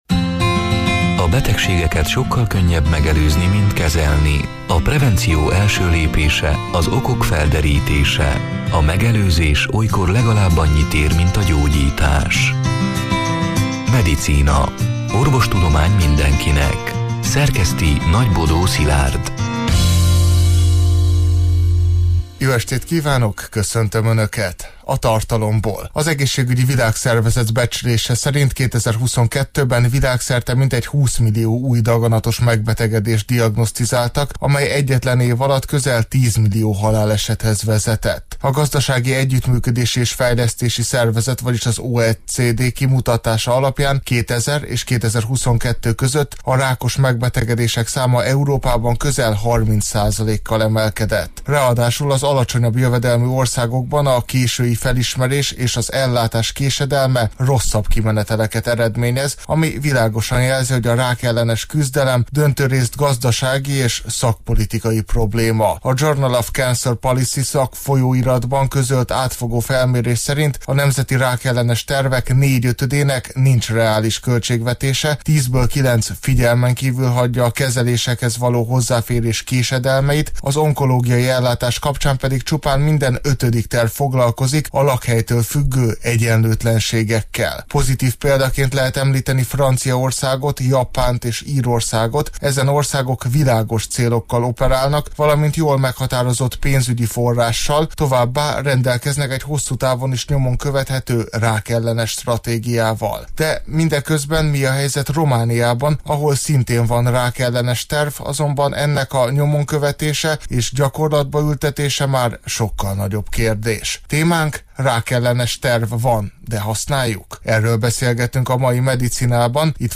A Marosvásárhelyi Rádió Medicina (elhangzott: 2026. február 18-án, szerdán este nyolc órától) c. műsorának hanganyaga: